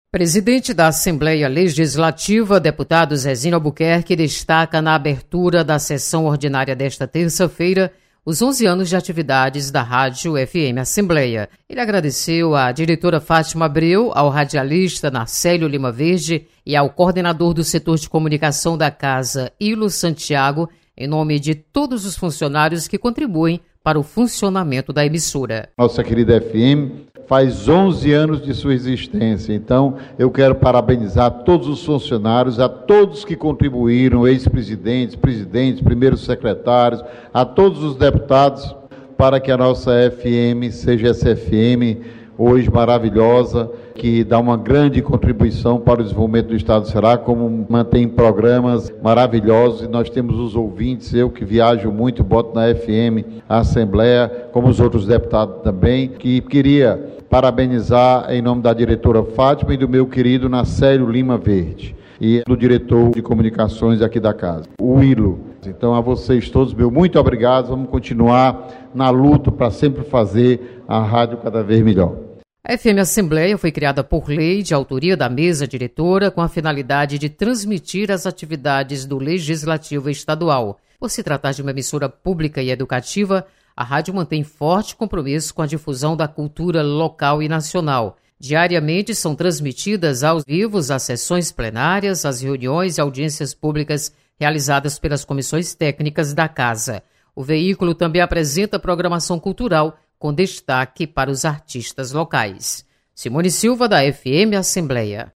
Deputado Zezinho Albuquerque, durante abertura da sessão ordinária, destaca 11 anos de criação da Rádio FM Assembleia. (1'45").